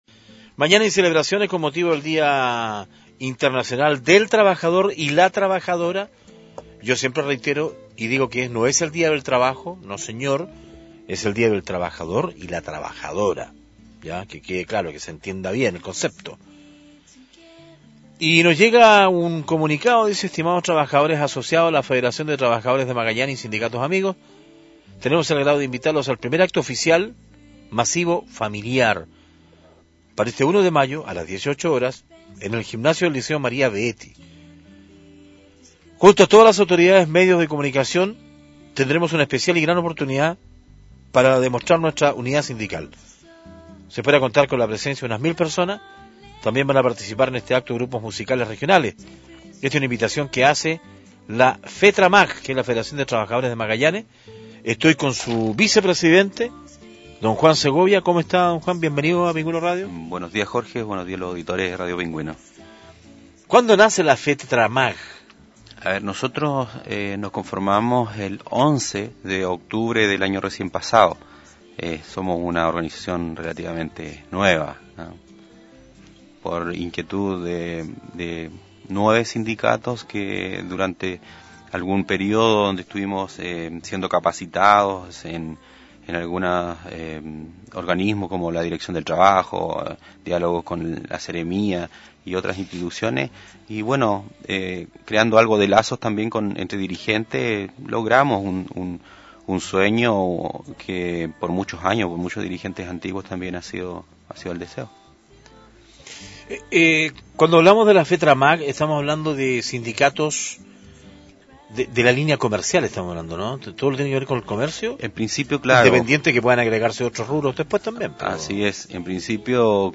Entrevistas de Pingüino Radio - Diario El Pingüino - Punta Arenas, Chile
Seremi del Trabajo y Economía